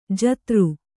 ♪ jatru